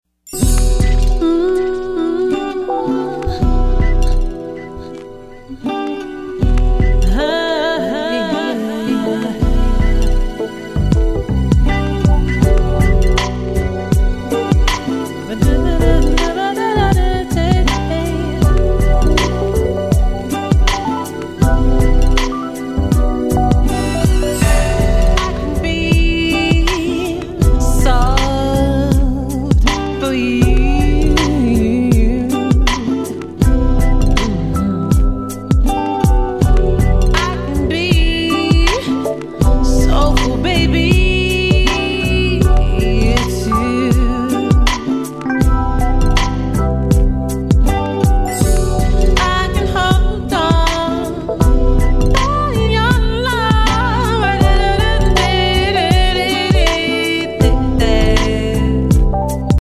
Atlanta Singer Songwriter 1
On the strength of her considerable talent, her presence and engaging personality delights and entertains audiences internationally with a unique blend of performance that is seductive, sensual, with interpretations of Jazz, Neo Soul, R&B and Latin blends that is stylized with her own signature.